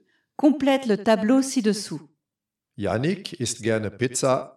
Effet d'écho indésirable sur des voix
Mais quand je me suis mis à l’édition sous casque, j’ai remarqué qu’il y avait un écho sur toutes les voix.
Voici un extrait : /uploads/default/original/3X/a/3/a3f9a806e1254e4ca9008f3ad308d3372a5b5a6e.mp3 Les deux intervenants sont installés dans une pièce, avec des micros individuels. Chaque micro est branché sur la table de mix qui renvoit le son sur un vieux Mac.